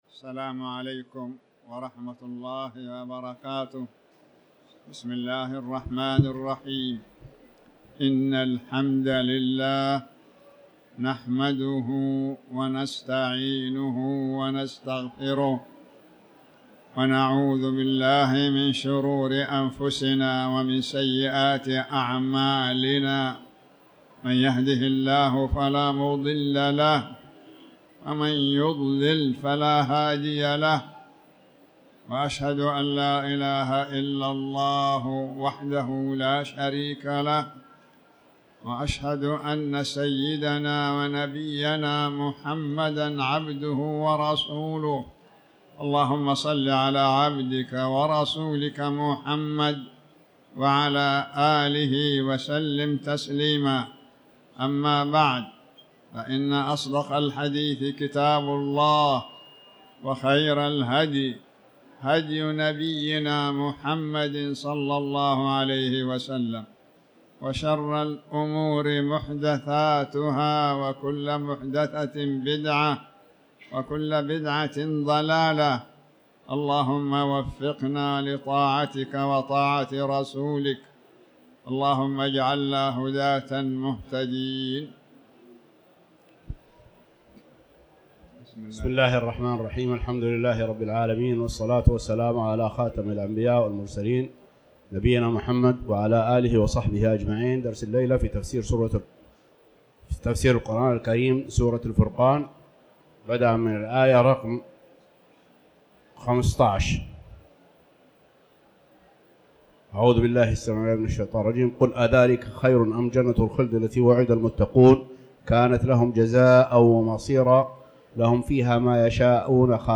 تاريخ النشر ٢٩ جمادى الآخرة ١٤٤٠ هـ المكان: المسجد الحرام الشيخ